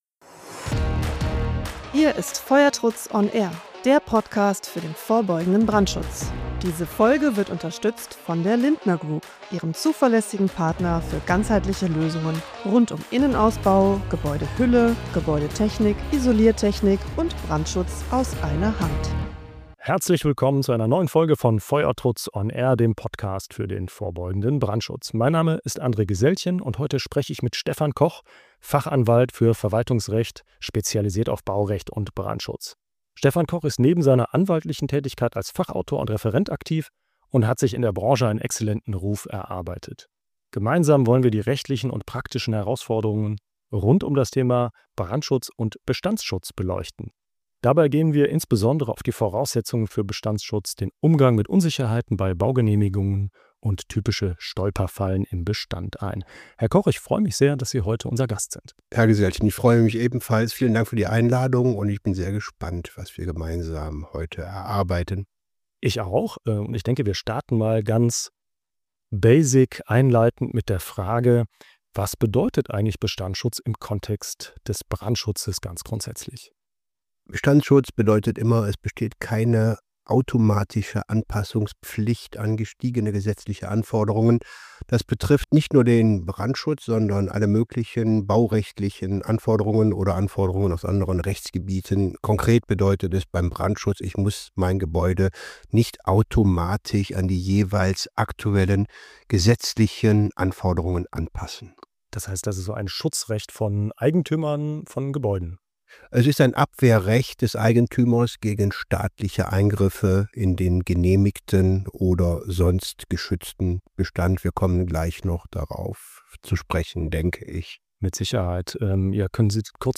Die beiden Gesprächspartner klären, wo Bestandsschutz beginnt und endet – und was die Projektbeteiligten bei baulichen Änderungen und Nutzungsänderungen wissen müssen.